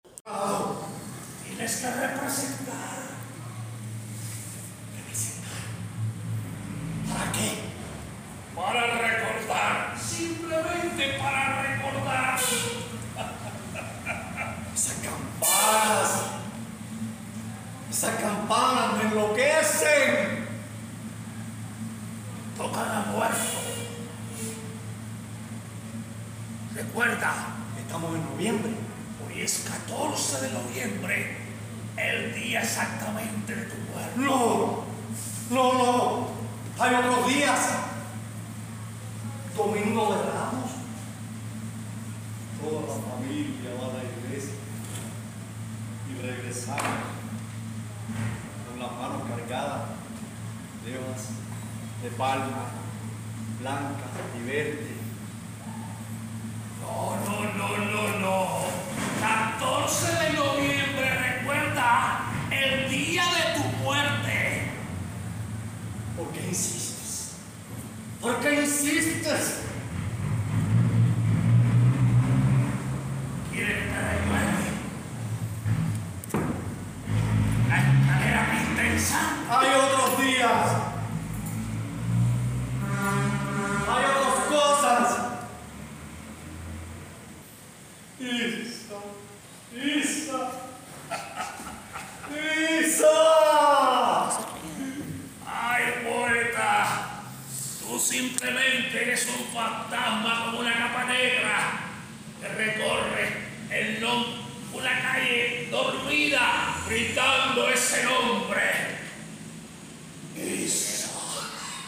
En la sala  Pedro Vera, artistas, docentes, educandos y amantes del teatro, se congregaron para recordar la vida y obra de un hombre que dejó una huella imborrable en la escena teatral de la isla.
Al público regalaron un fragmento promocional del próximo estreno, No me atormentes más, inspirada en la obra Vagos Rumores.